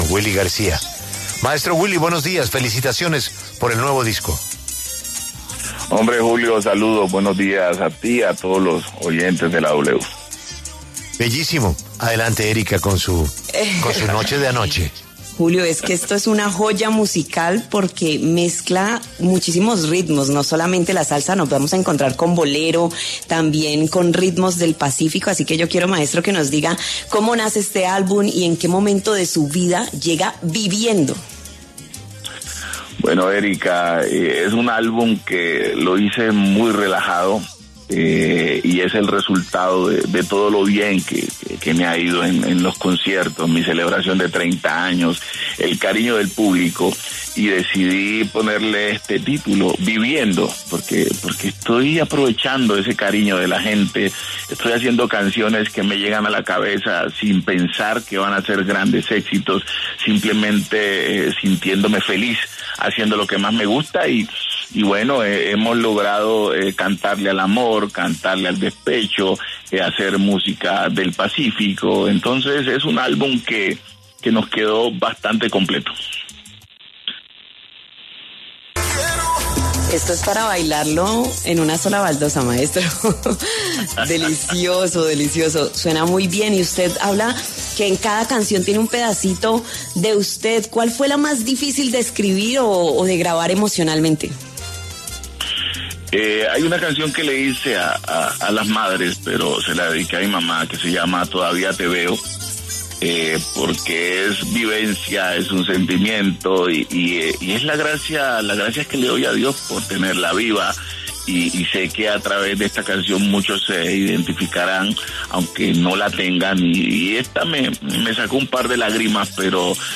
El reconocido cantante de salsa Willy García pasó por los micrófonos de La W con Julio Sánchez Cristo para hablar sobre su más reciente proyecto musical, su nuevo álbum llamado ‘Viviendo’.